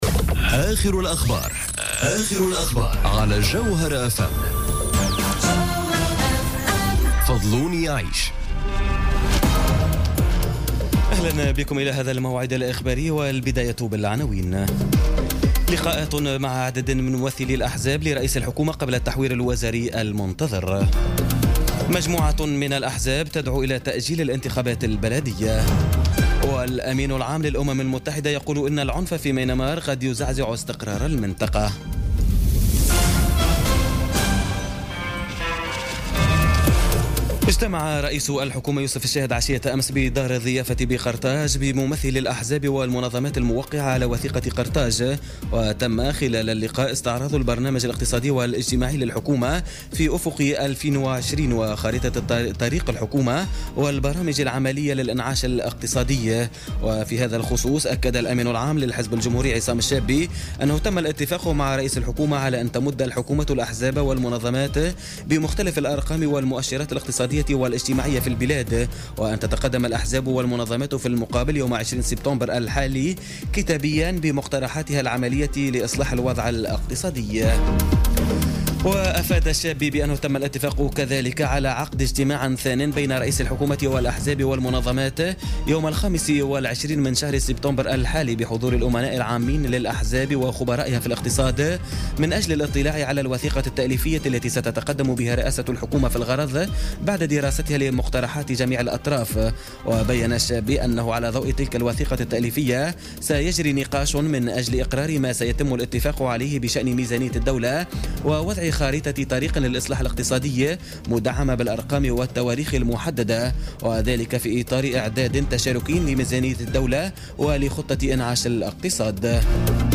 نشرة أخبار منتصف الليل ليوم الاربعاء 6 سبتمبر 2017